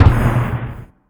jumppad.ogg